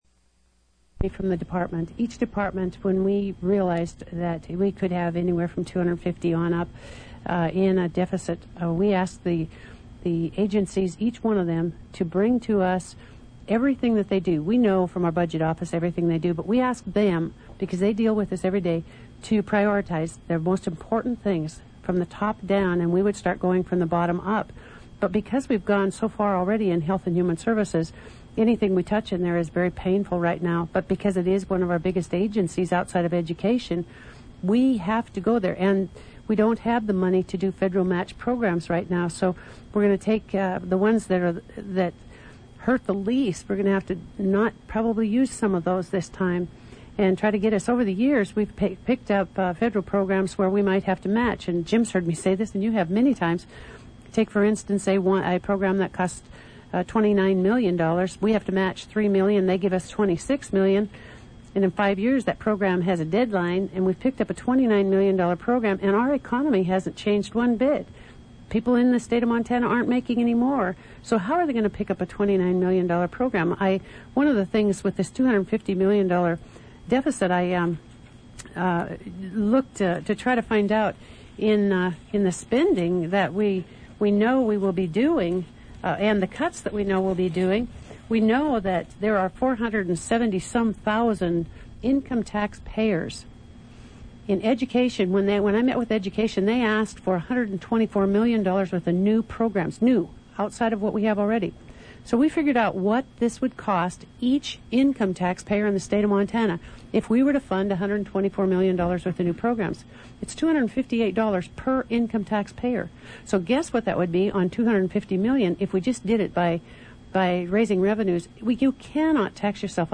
(2 hours, 9 minutes) Play Undated, early 2000s What's Happening in Helena - An excerpt of an interview with Montana governor Judy Martz and the head of the Dept. of Health and Human Services.